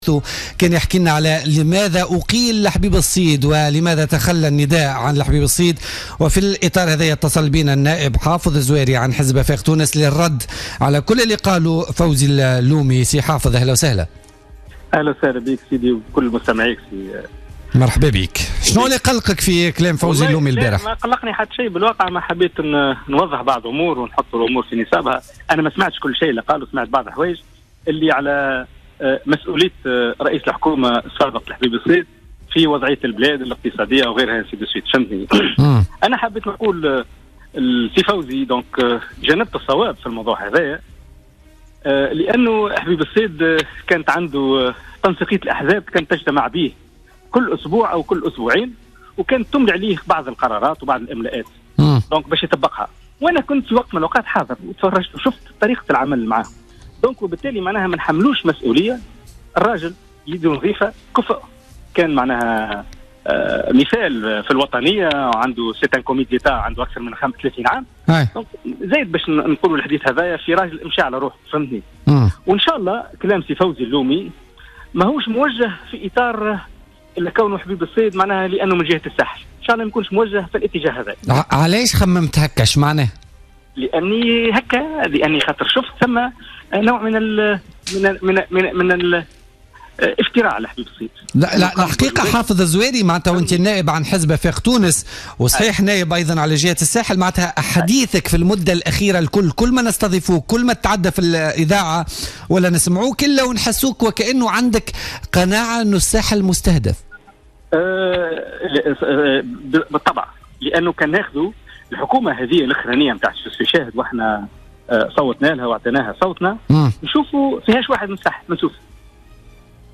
أكد النائب عن حركة افاق تونس حافظ الزواري في مداخلة له في بوليتيكا اليوم 1 نوفمبر...